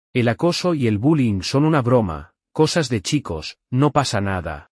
Creado por IA con CANVA